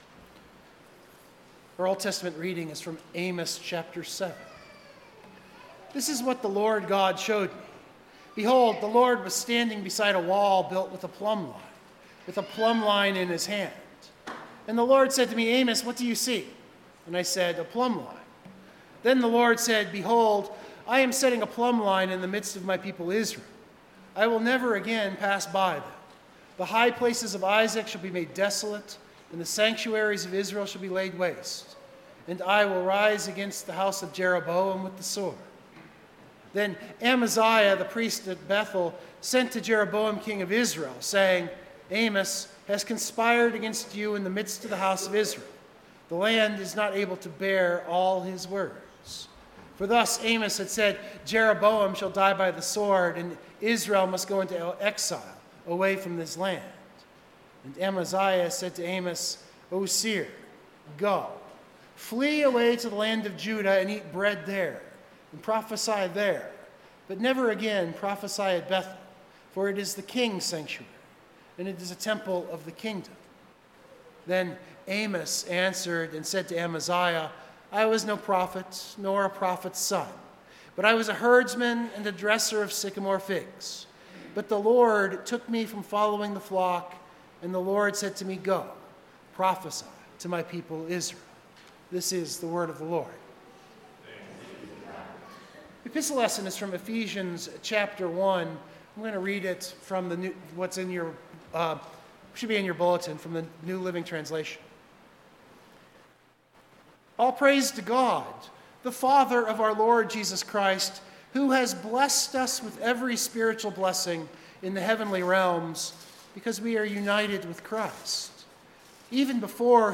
Biblical Text: Ephesians 1:3-14 NLT Full Sermon Draft